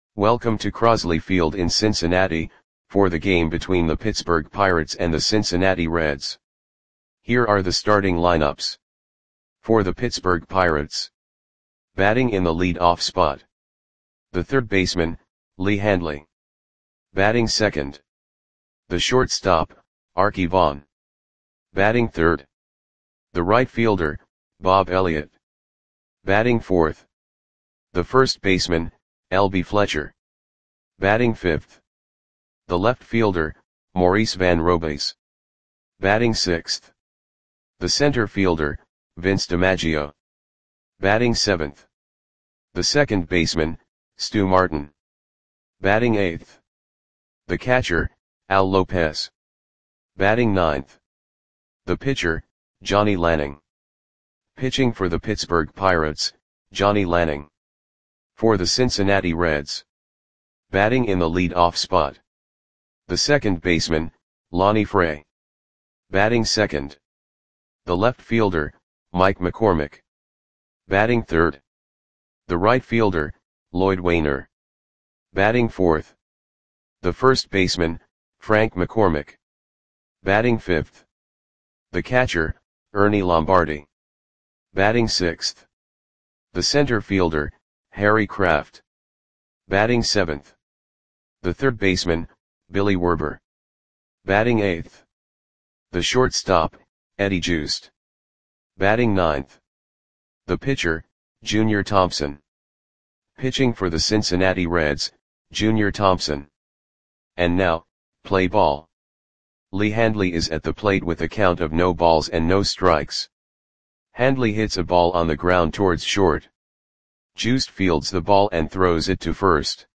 Lineups for the Cincinnati Reds versus Pittsburgh Pirates baseball game on August 4, 1941 at Crosley Field (Cincinnati, OH).
Click the button below to listen to the audio play-by-play.